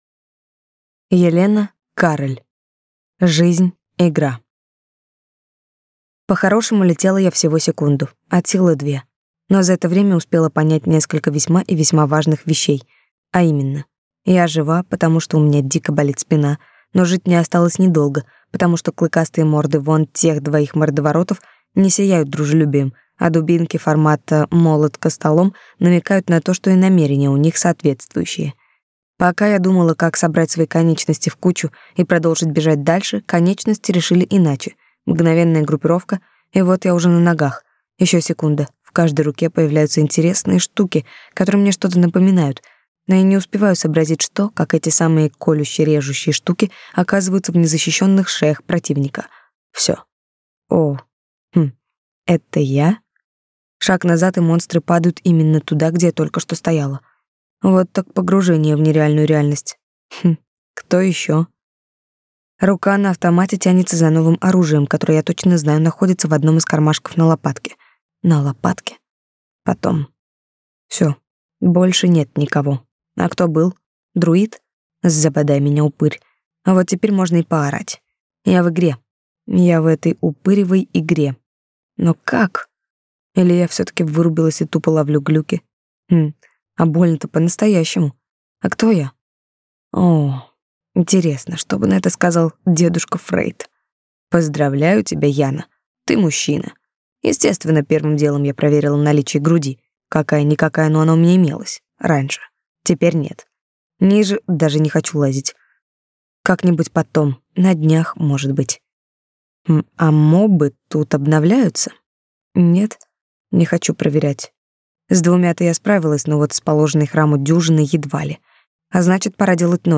Аудиокнига Жизнь – игра | Библиотека аудиокниг